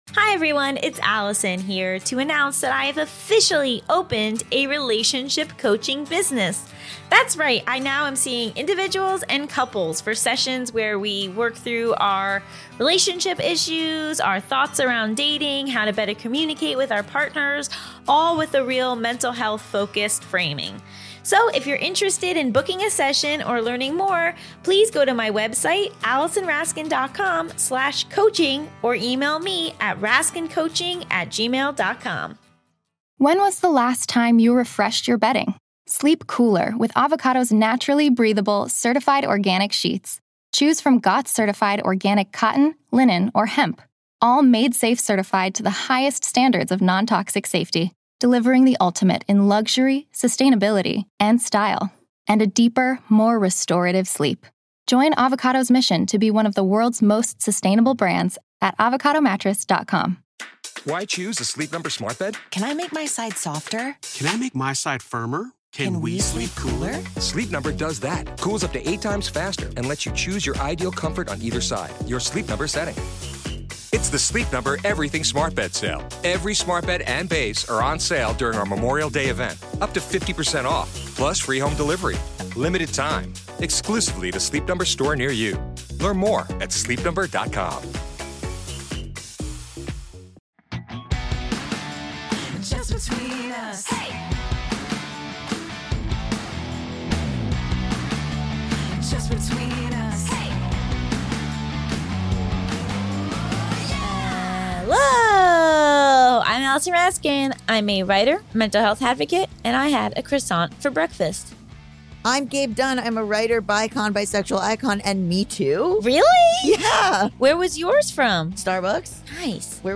Mental Health, Education, Sexuality, Comedy, Self-improvement, Relationships, Comedy Interviews, Society & Culture, Personal Journals, Health & Fitness